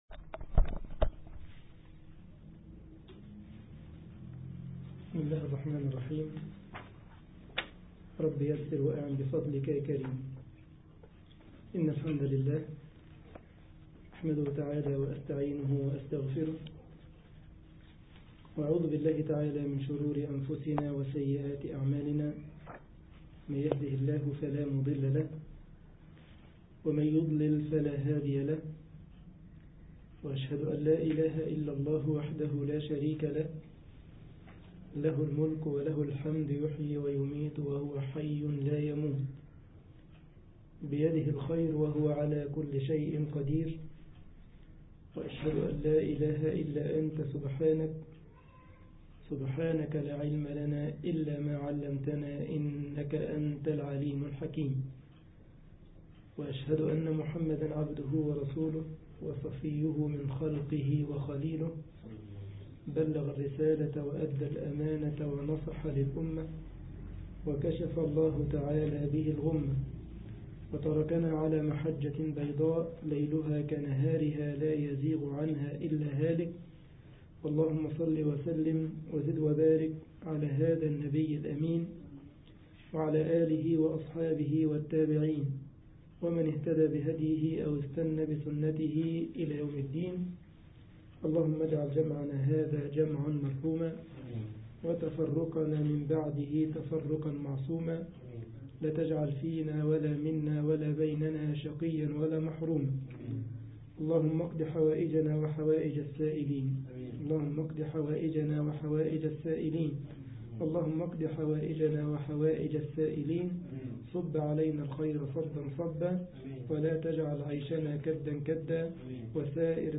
مصلى جامعة السارلند ـ ألمانيا